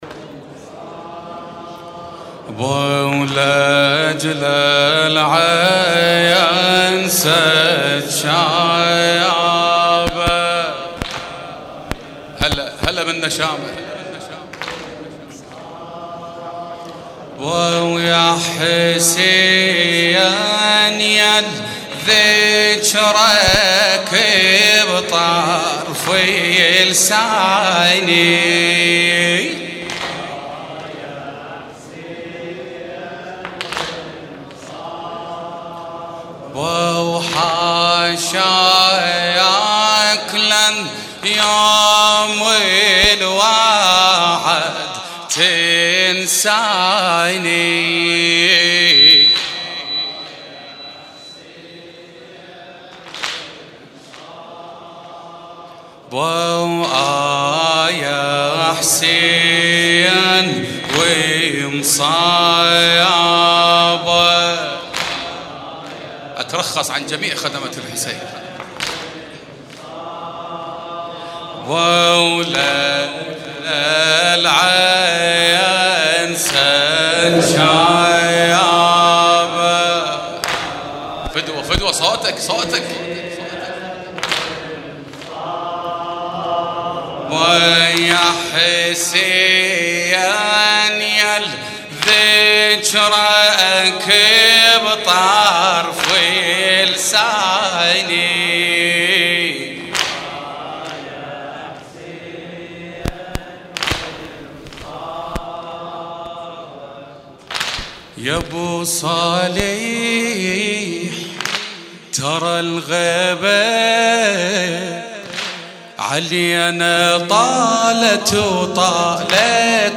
قصیده شب اول محرم 1447